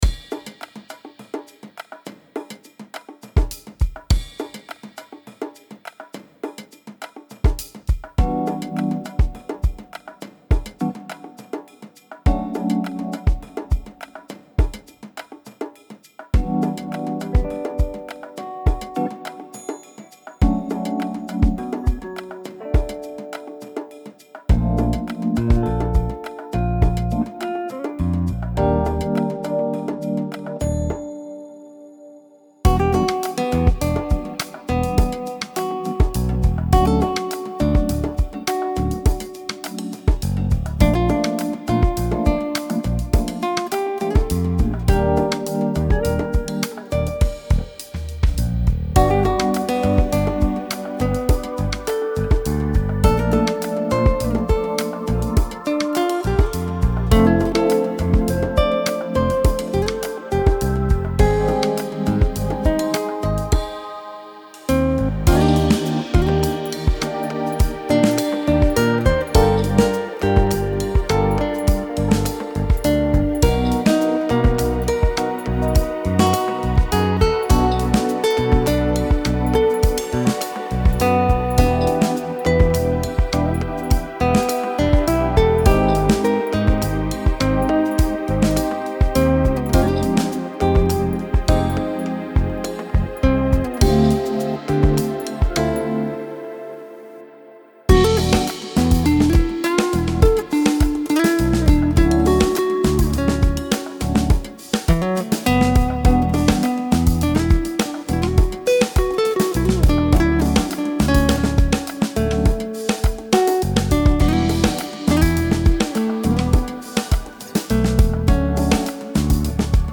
Самая лютая - что звучание не доводится до уровня коммерческого продукта.